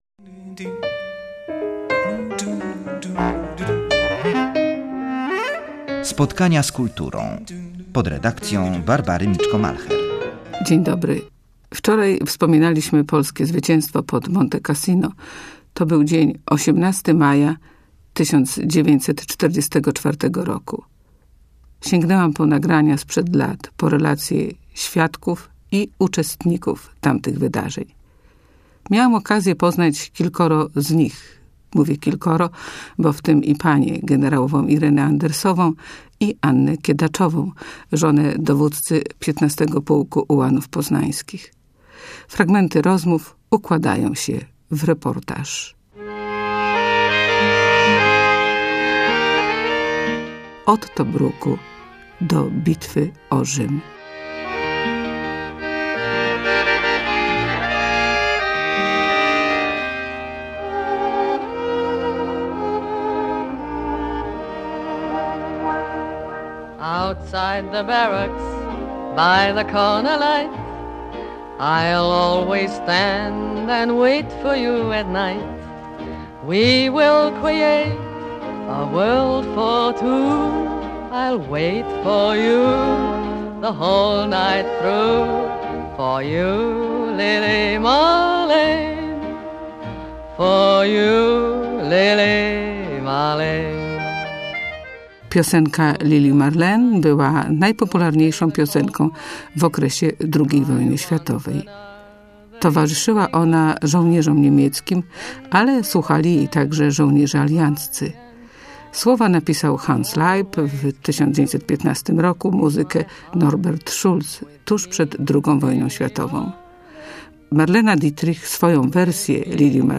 Poznaniacy na frontach II Wojny Światowej. Ich opowieści i relacje układają się w reportaż przybliżający tamte wojenne wydarzenia.